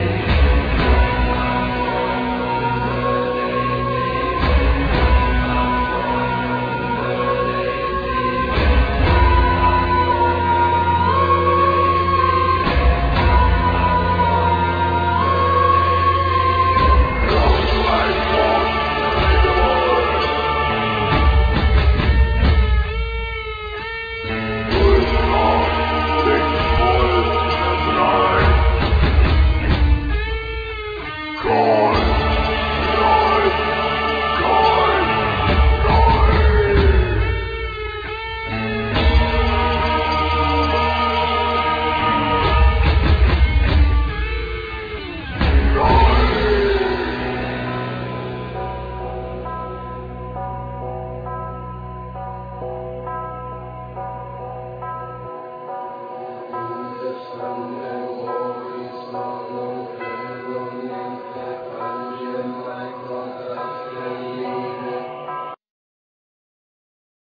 Guitar,Mandola,Voices
Drums,Percussion,Voices
Bass,Zurna,Saz,Flutes,Vocals
Text Performance
Cello